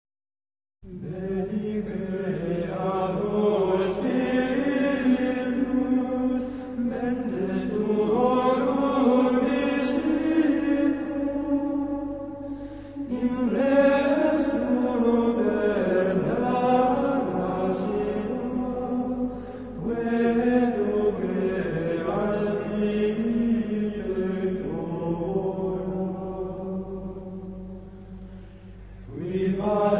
Himno (Modo 8.)